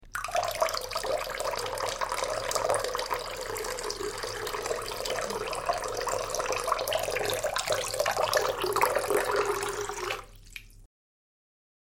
دانلود آهنگ آب 49 از افکت صوتی طبیعت و محیط
دانلود صدای آب 49 از ساعد نیوز با لینک مستقیم و کیفیت بالا
جلوه های صوتی